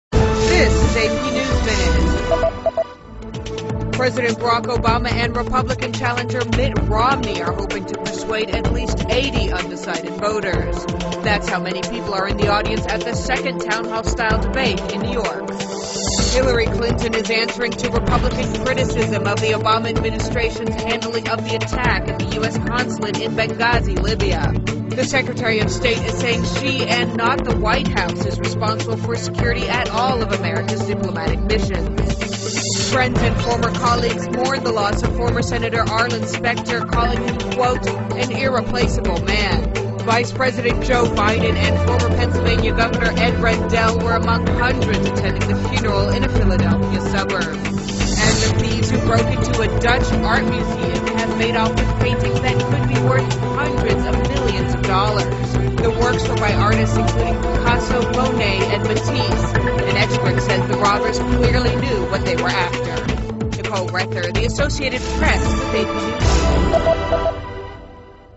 在线英语听力室美联社新闻一分钟 AP 2012-10-19的听力文件下载,美联社新闻一分钟2012,英语听力,英语新闻,英语MP3 由美联社编辑的一分钟国际电视新闻，报道每天发生的重大国际事件。电视新闻片长一分钟，一般包括五个小段，简明扼要，语言规范，便于大家快速了解世界大事。